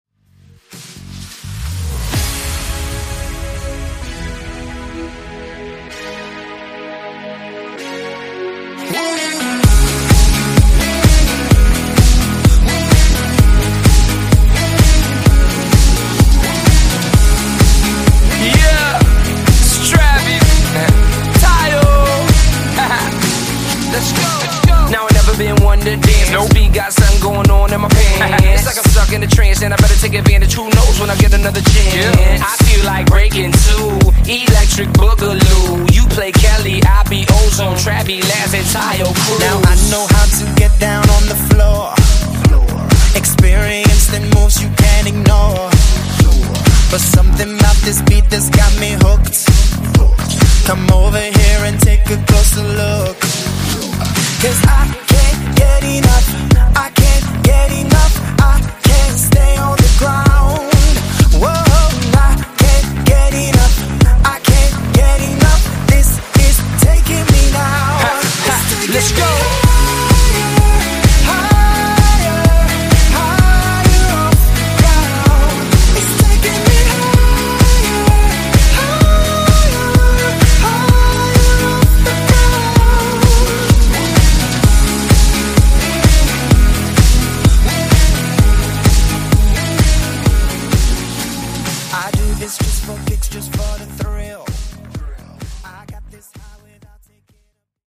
Genres: DANCE , EDM , RE-DRUM
Clean BPM: 128 Time